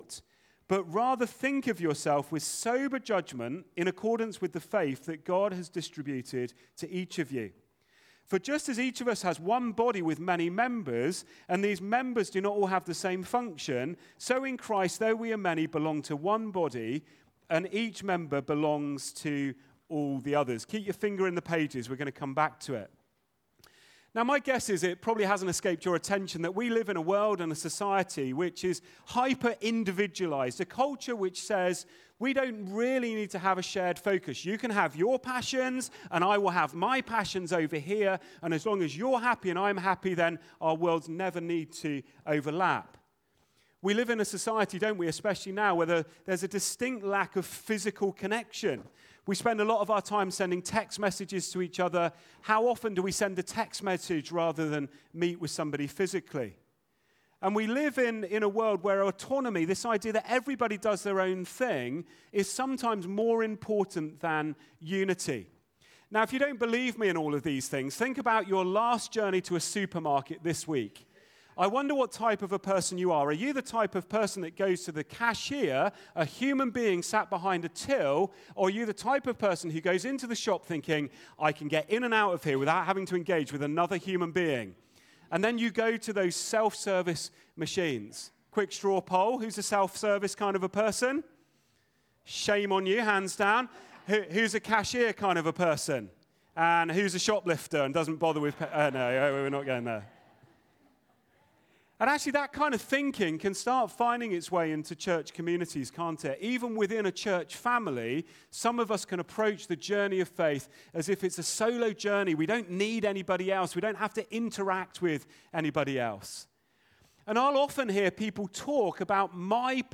Sermon Audio - Christchurch Baptist Church